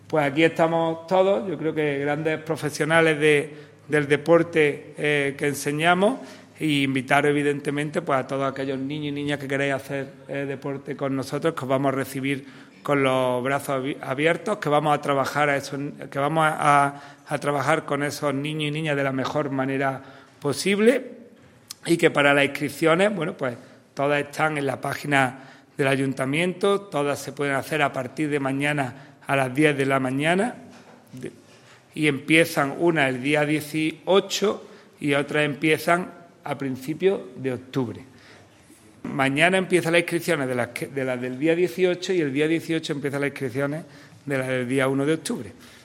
El teniente de alcalde delegado de Deportes, Juan Rosas, ha presentado hoy lunes en rueda de prensa el grueso de las escuelas deportivas municipales que compondrán la oferta al respecto del Área de Deportes del Ayuntamiento de Antequera.
Cortes de voz